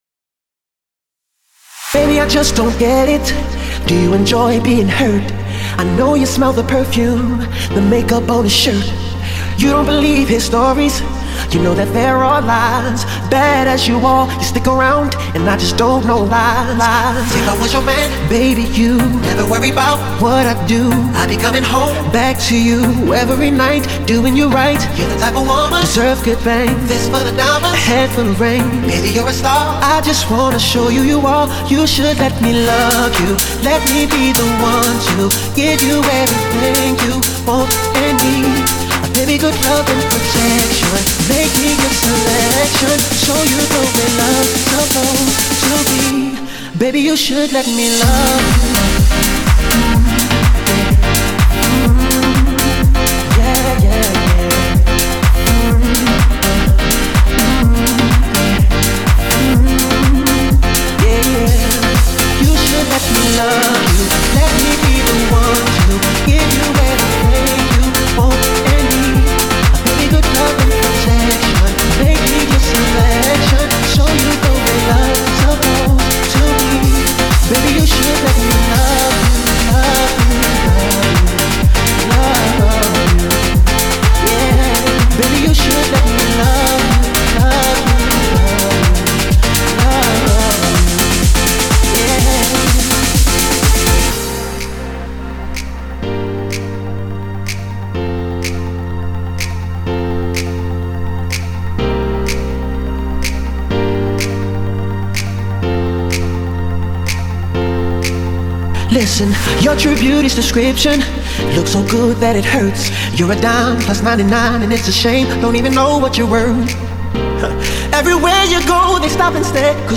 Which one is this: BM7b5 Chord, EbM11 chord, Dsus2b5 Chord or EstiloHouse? EstiloHouse